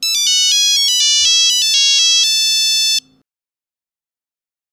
Monophonic